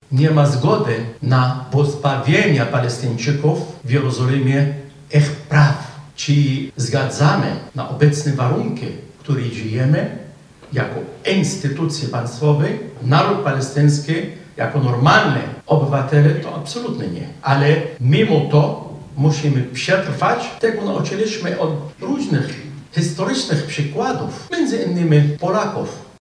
O prawach Palestyńczyków mówił na Katolickim Uniwersytecie Lubelskim ambasador Palestyny w Polsce, Mahmoud Khalifa.